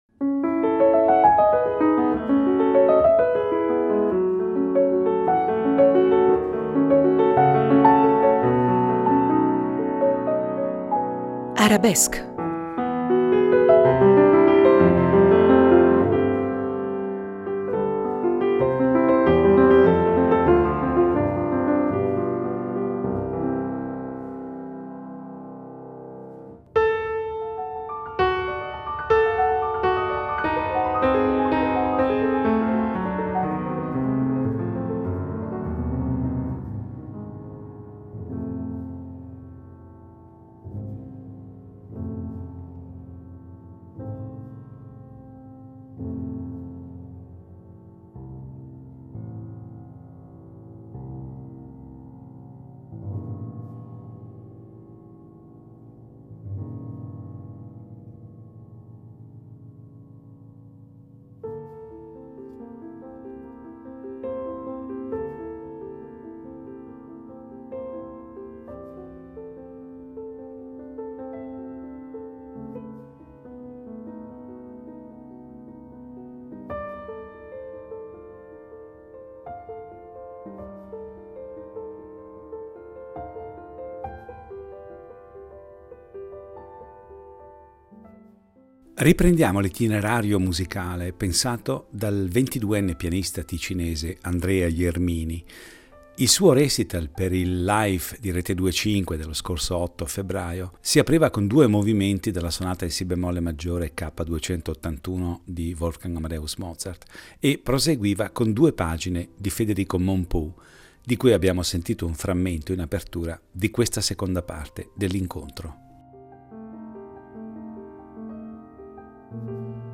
la serie di concerti
il giovane pianista ticinese